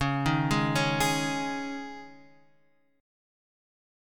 C#sus2 Chord